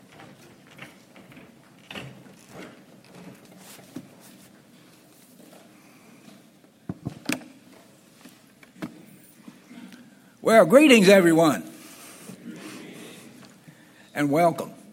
Given in Morehead City, North Carolina
Feast of Tabernacles 2025 Opening Night Message- Morehead City.